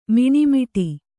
♪ miṇi miṭi